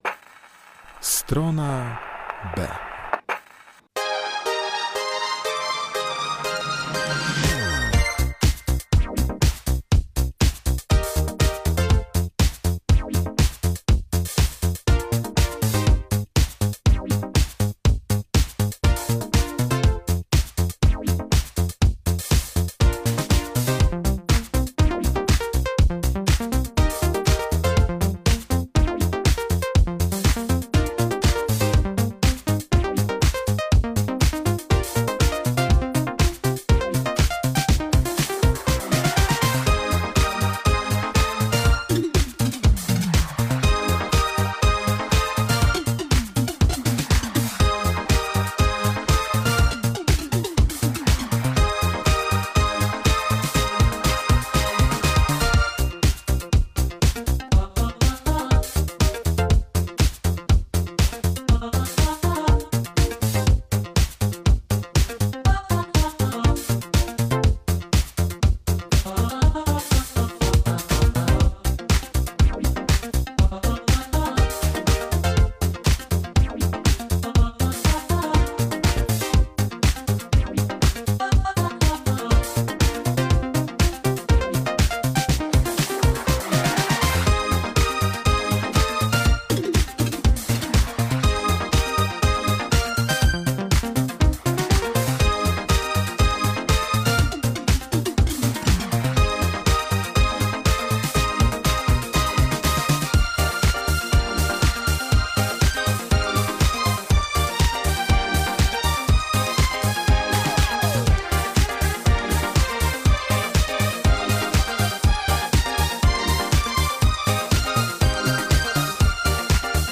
STRONA B - godzina z elektroniką, ambient, post punkiem i shoegaze.